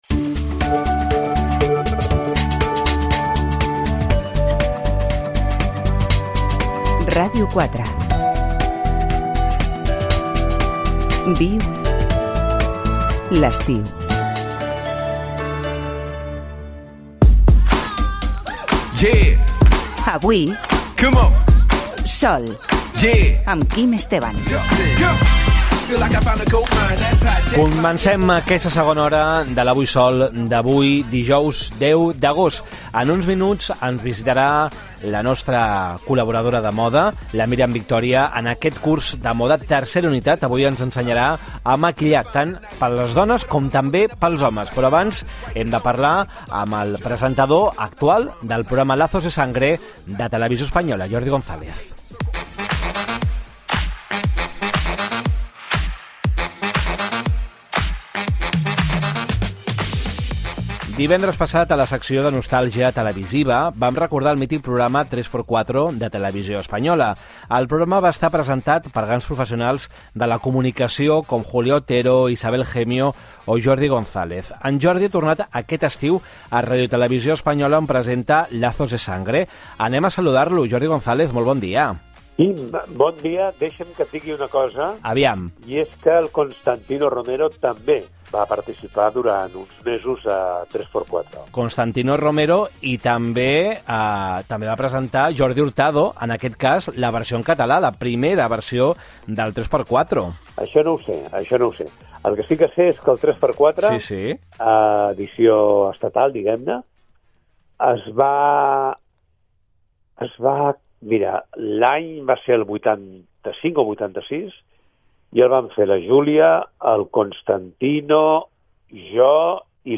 36ee9b36b42a3419ae6b2d778dfa6334ed889362.mp3 Títol Ràdio 4 Emissora Ràdio 4 Cadena RNE Titularitat Pública estatal Nom programa Avui, sol Descripció Indicatius de l'emissora i del programa.
Entrevista al presentador Jordi González sobre la seva trajectòria professional a la ràdio i la televisió